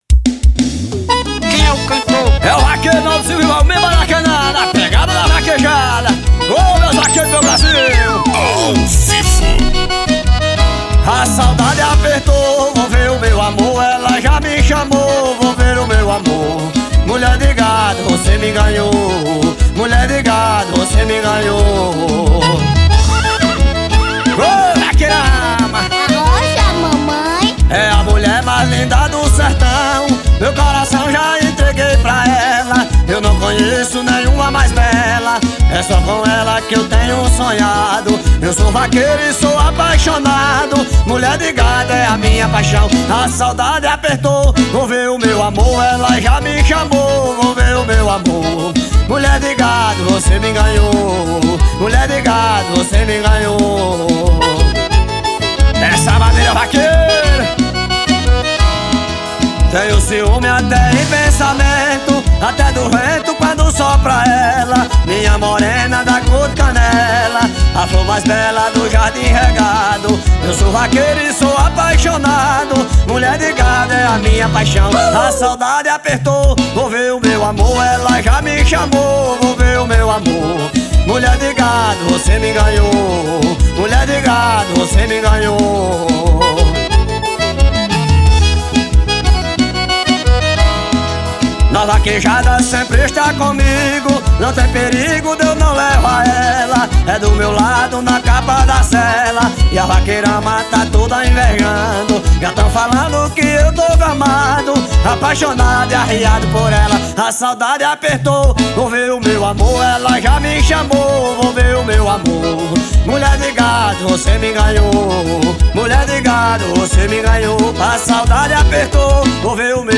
AO VIVO 2016.